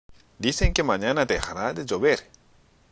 ＜発音と日本語＞
（ディセンケ　マニャーナ　デハラ　デ　ジョベール）